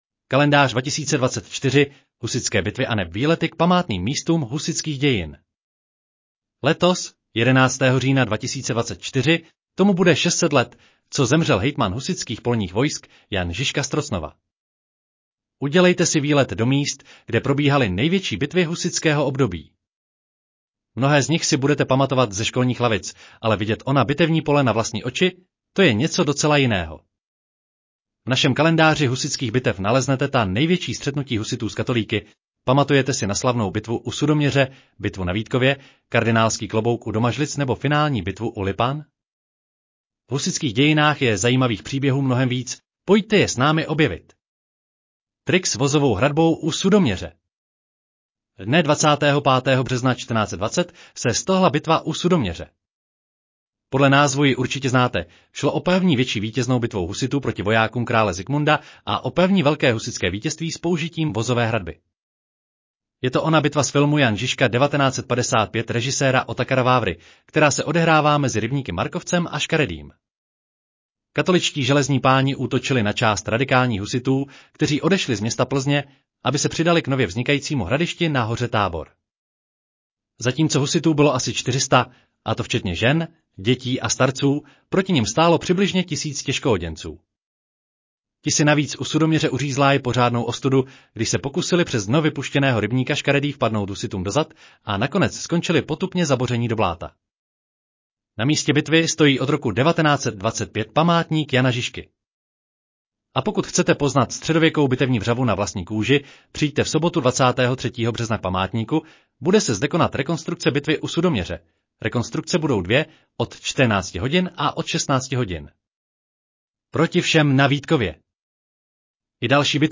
Audio verze článku Kalendář 2024: Husitské bitvy aneb výlety k památným místům husitských dějin